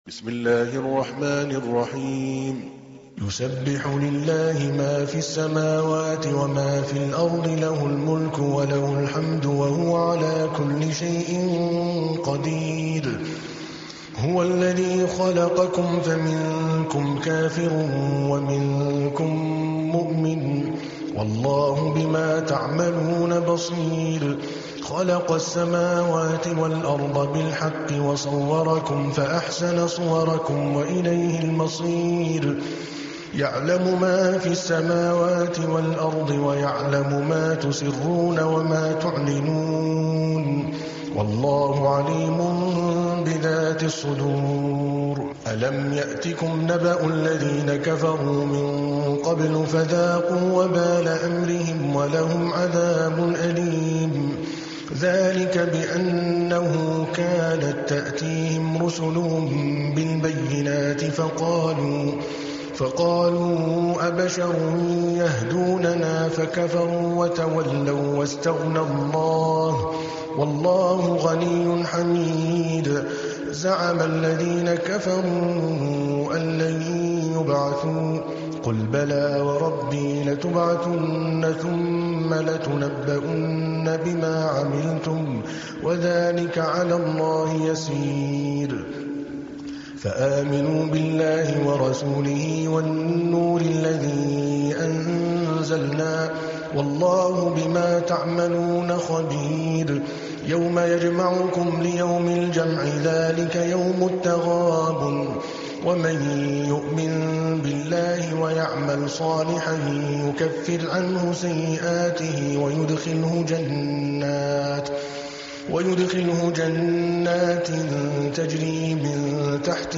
تحميل : 64. سورة التغابن / القارئ عادل الكلباني / القرآن الكريم / موقع يا حسين